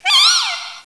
sound / direct_sound_samples / cries / amoonguss.aif
amoonguss.aif